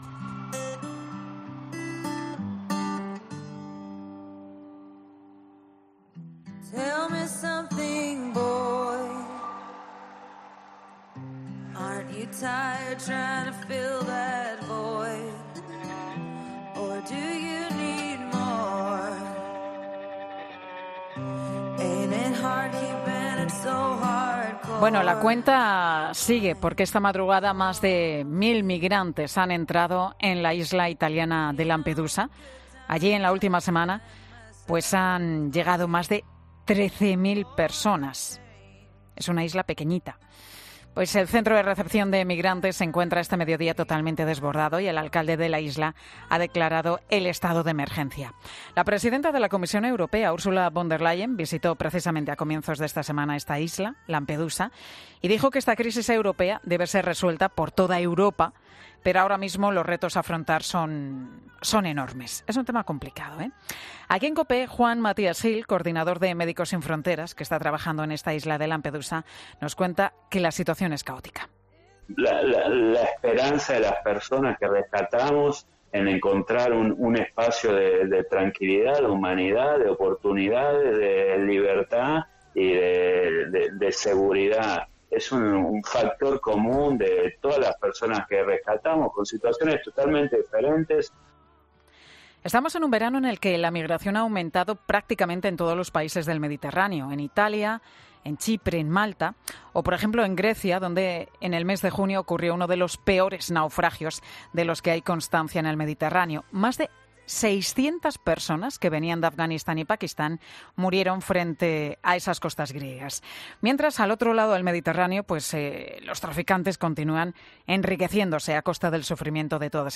En representación de la Conferencia Episcopal Española participan, están asistiendo su presidente, el Cardenal Juan José Omella, el obispo de Málaga Jesús Catalá y también el obispo de Cádiz y Ceuta, Rafael Zornoza que nos ha atendido en los micrófonos de Mediodía COPE.